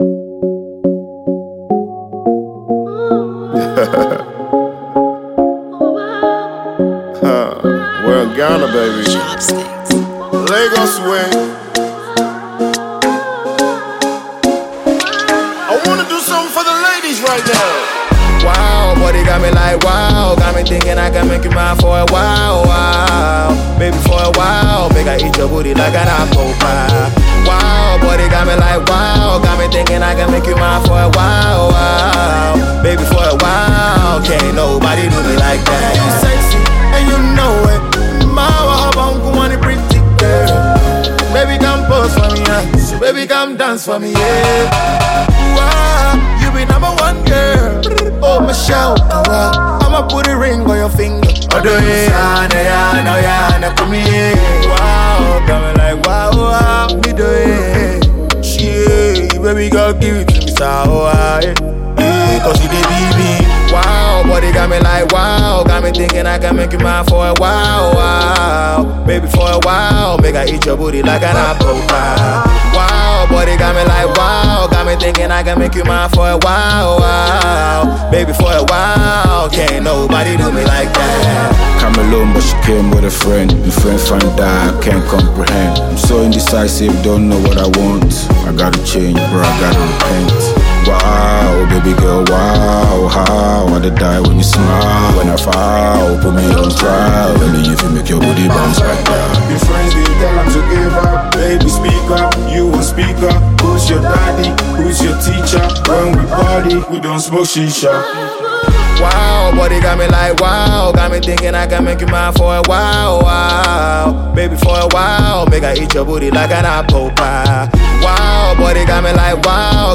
Ghanaian Afropop/Afrobeat singer and songwriter